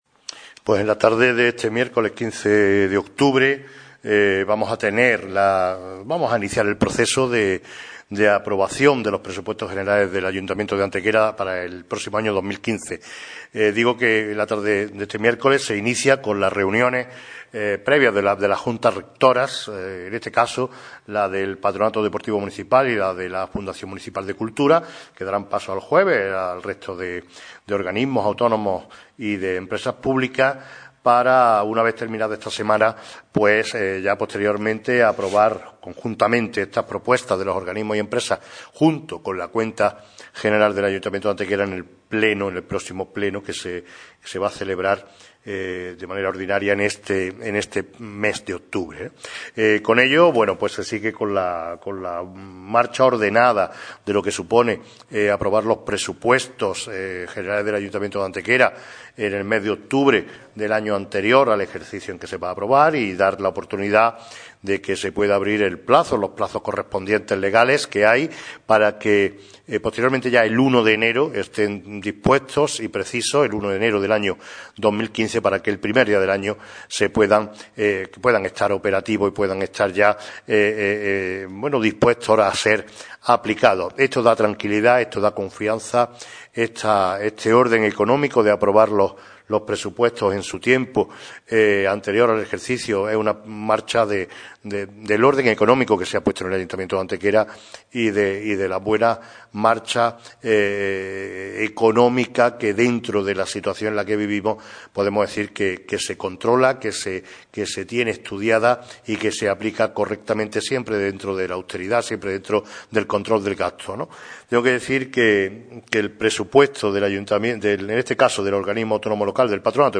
Cortes de voz M. Barón 1732.52 kb Formato: mp3